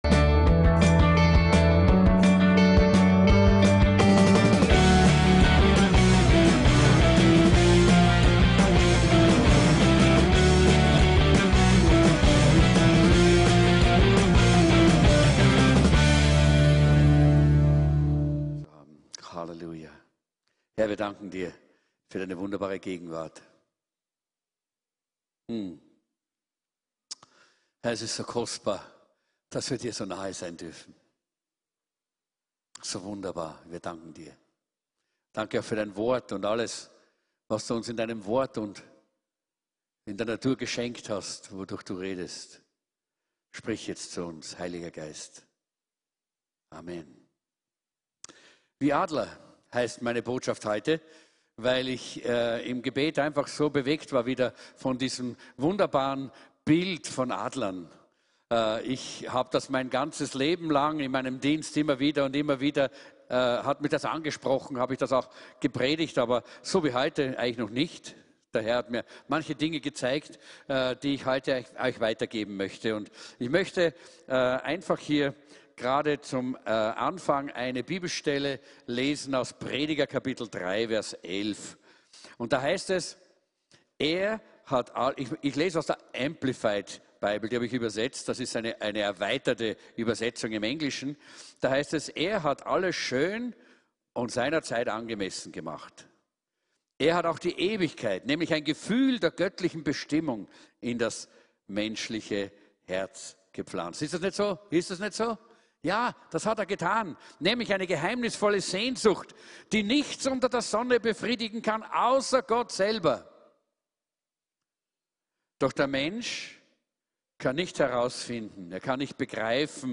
VCC JesusZentrum Gottesdienste (audio)